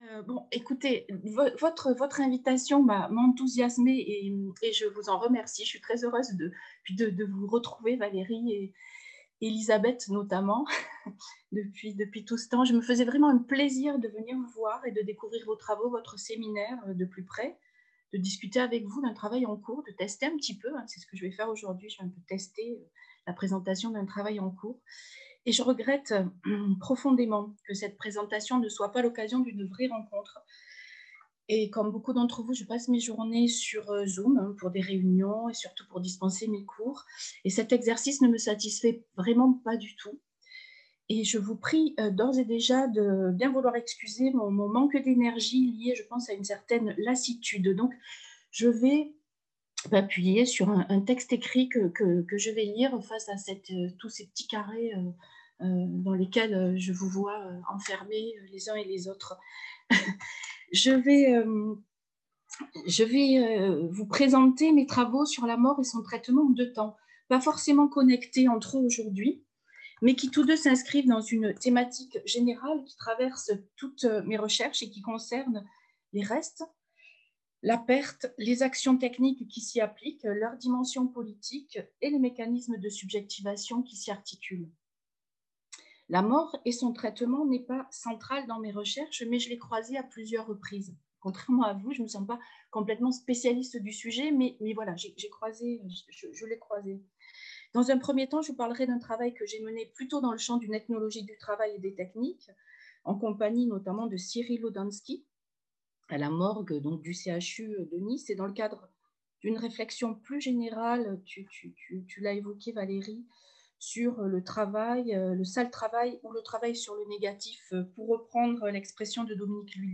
Enregistrement audio sur Zoom.